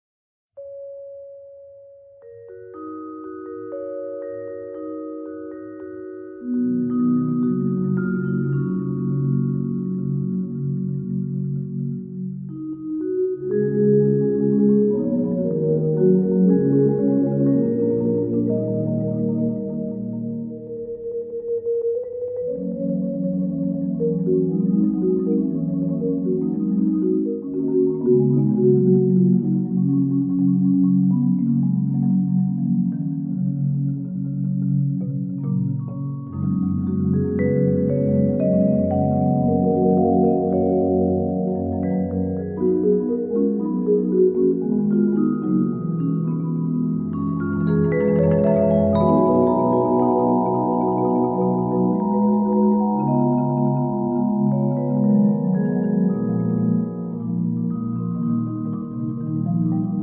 chamber percussion group